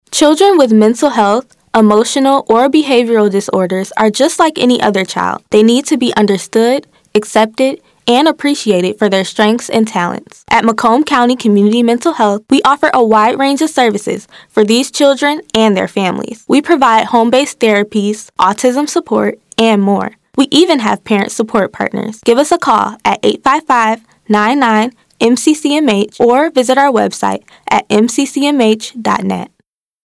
Your Mental Health radio spot